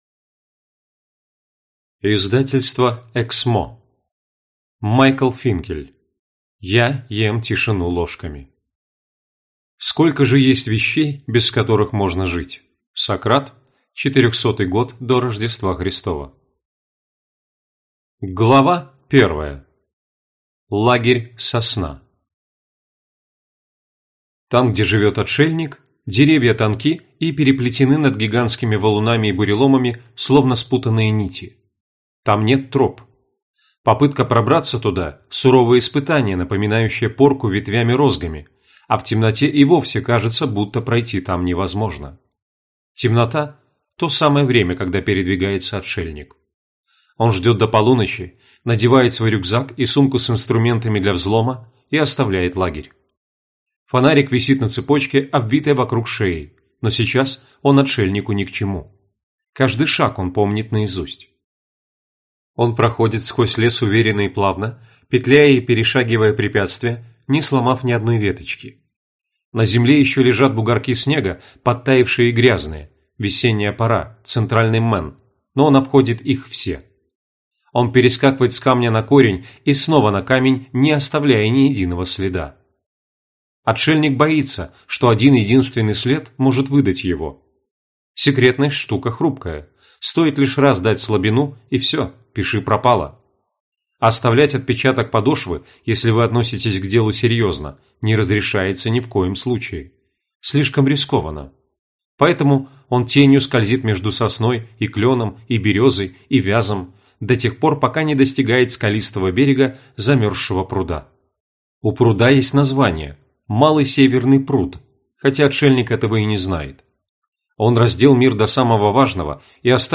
Аудиокнига Я ем тишину ложками | Библиотека аудиокниг
Читает аудиокнигу